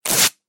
2. Ну это всем нам знакомый звук отклеивания скотча